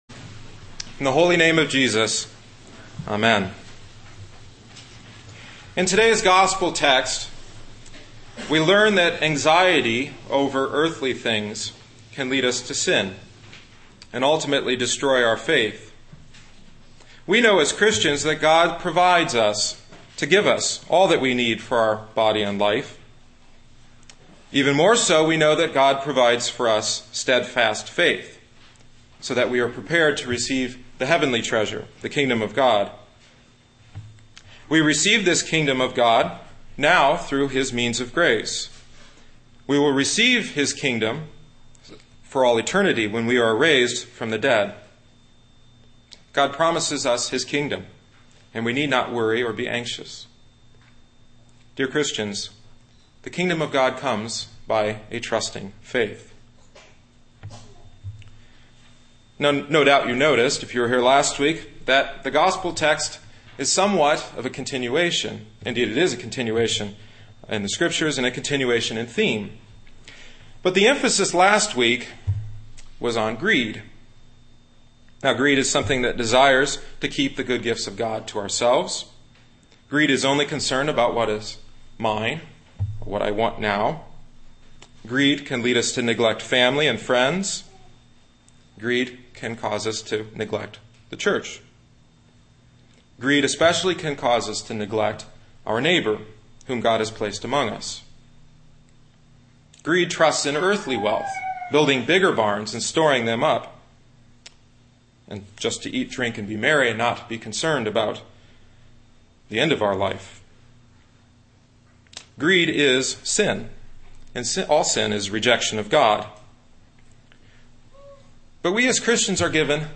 Grace Lutheran Church – Dyer, Indiana